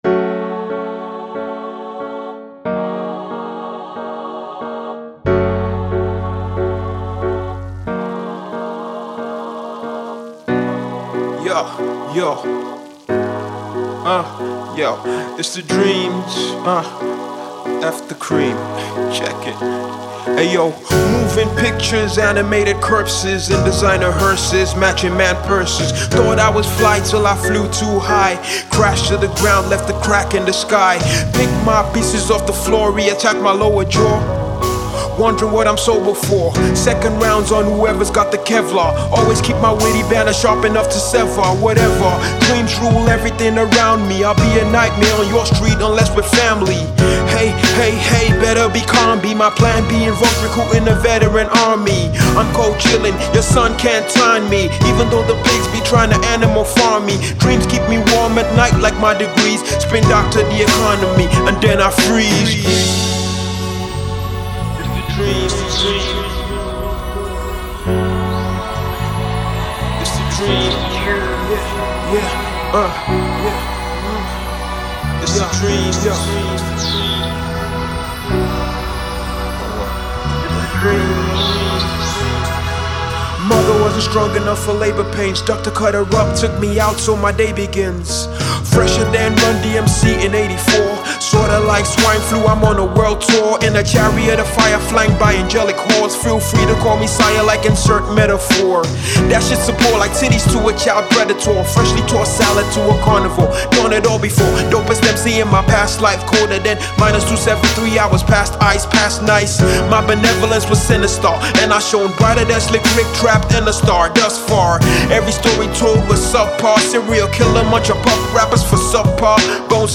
Note*recommended for Hip-Hop Heads…